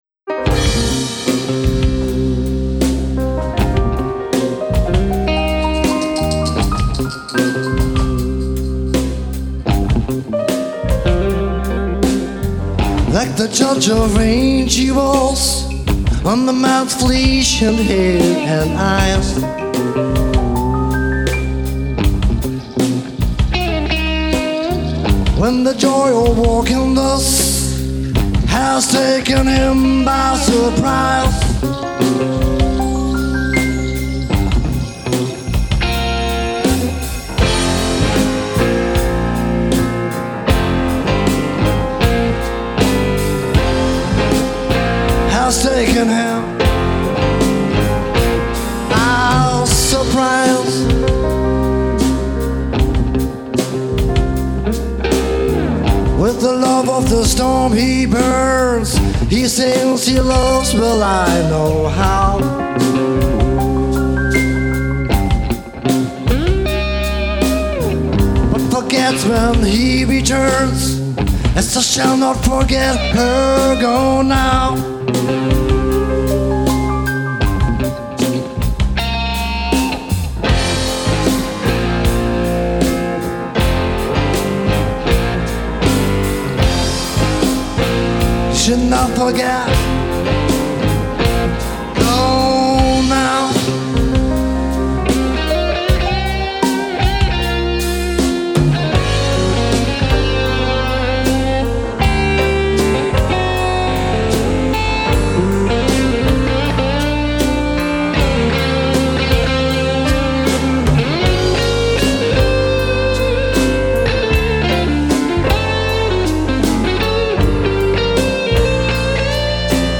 Mit Acryl auf einem 60.Geburtstag:
Aufgenommen wurde das mit einem 24-Spur-Recorder.